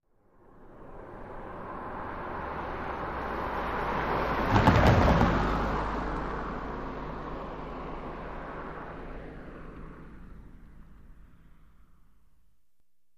Car Tires Over Railroad Tracks 4x